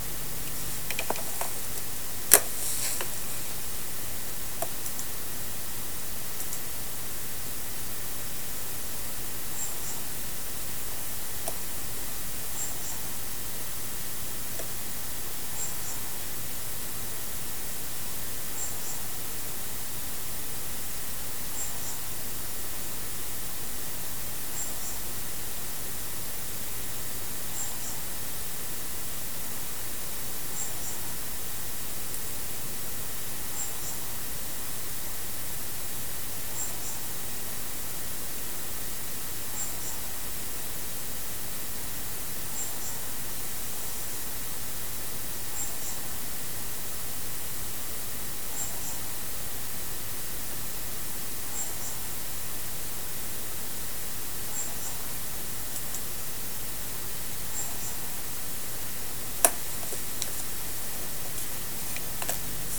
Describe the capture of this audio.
Â This below is something in the air projected by directional ultrasound speakers: a loop play recording of “say something”. The words can act as trigger words to manipulate thinking/perception. It is an in air recording by a parabolic microphone.